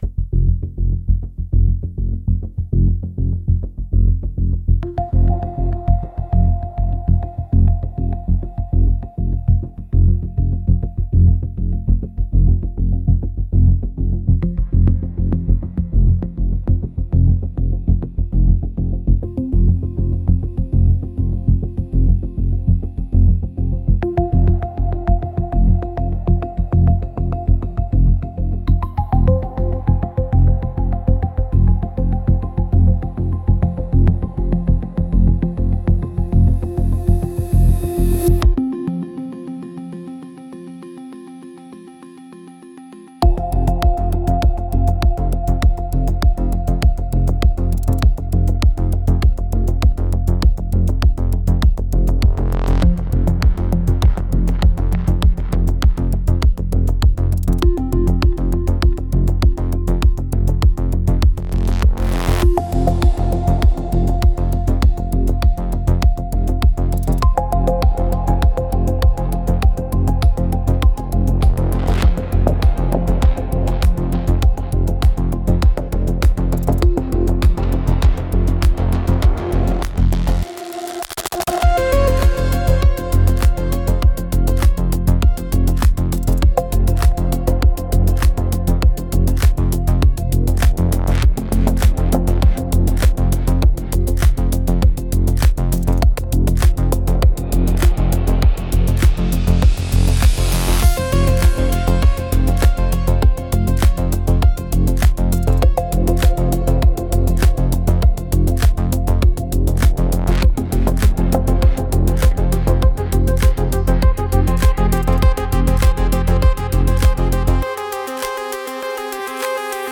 S O U N D S C A P E